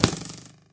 bowhit1.ogg